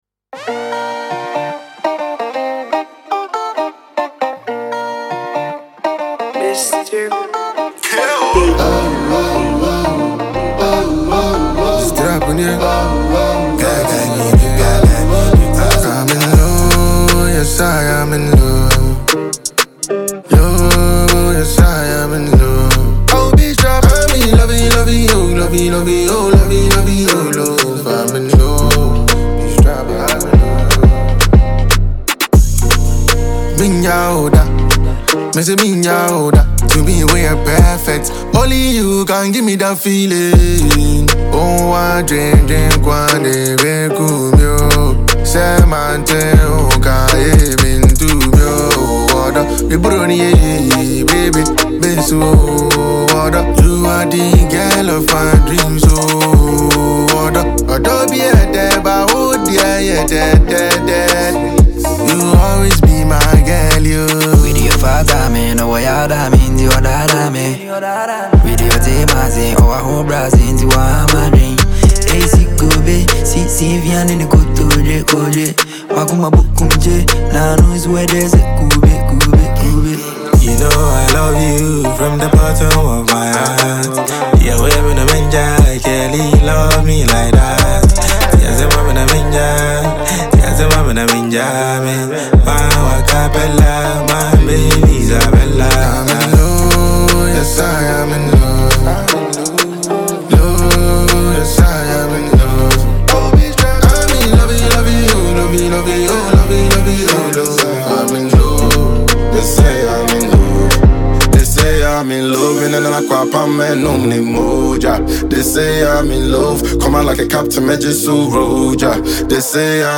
a Ghanaian trapper
Enjoy this magical production from this rapper.